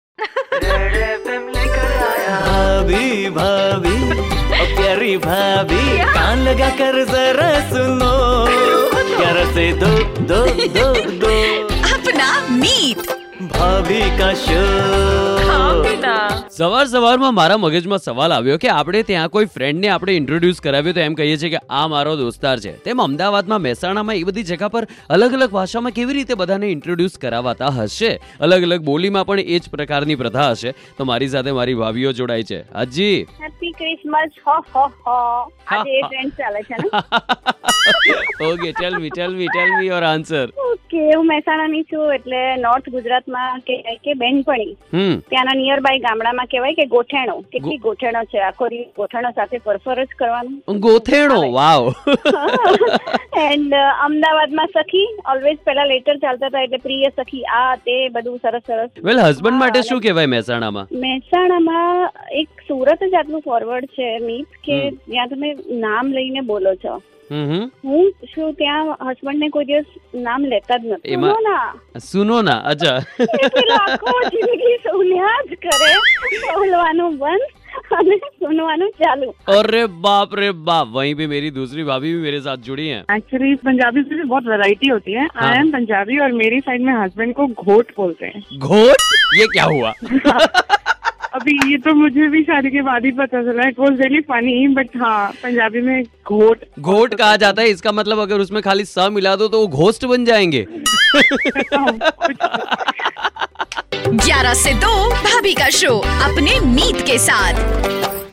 Family introduction in different dialects